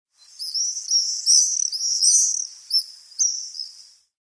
Вы услышите их щебет, трели и другие голосовые реакции, которые помогут вам ближе познакомиться с этими удивительными птицами.
Звук ласточек, летающих в небе и пищащих